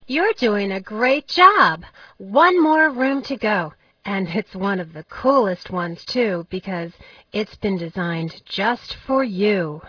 Sound: Listen to Klio (a Muse!) tell you the instructions (10").